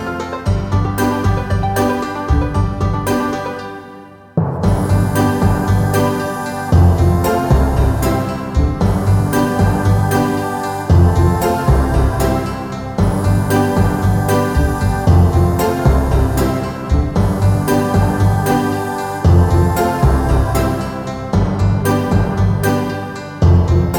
no sung Backing Vocals Easy Listening 4:28 Buy £1.50